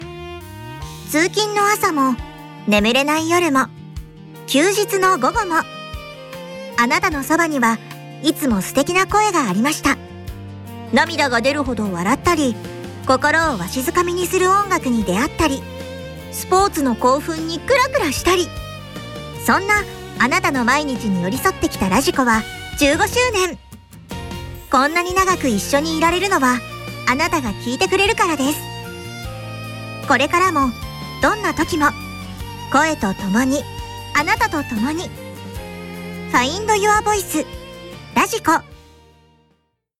radiko15周年CM 20秒/40秒